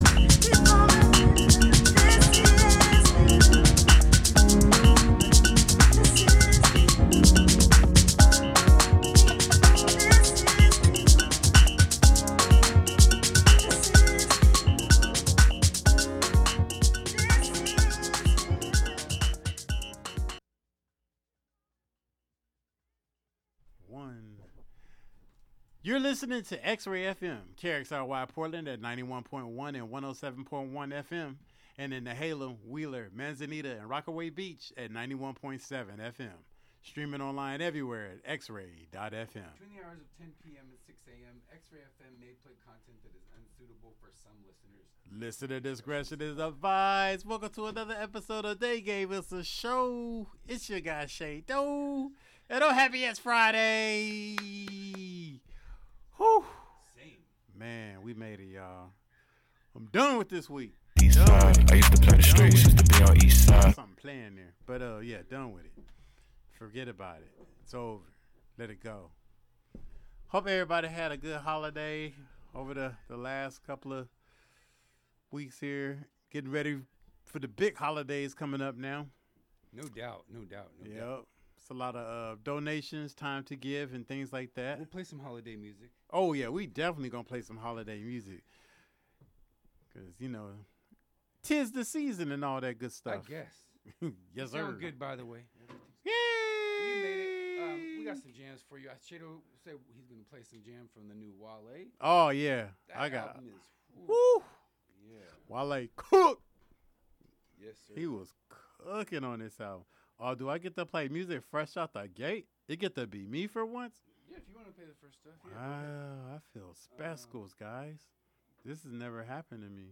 New and throwbacks, from Portland and beyond. Plus, catch interviews from talented artists.